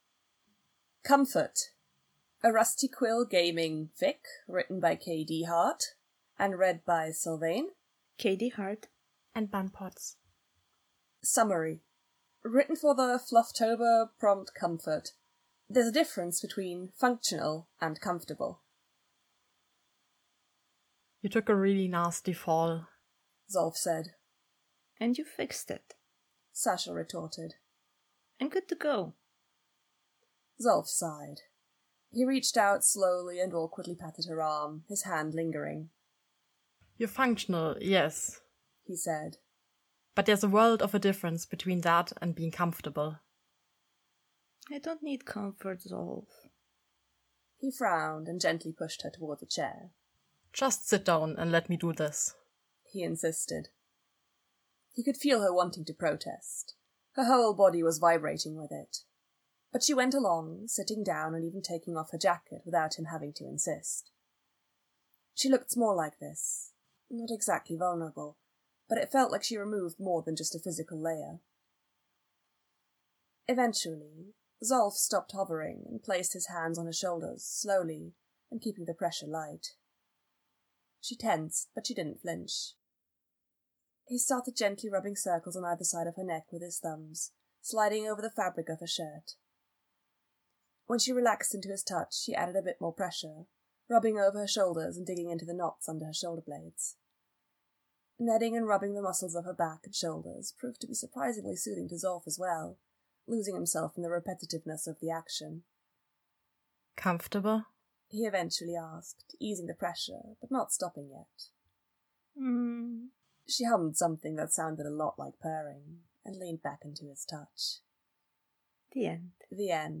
Recorded during EuropodFriends2020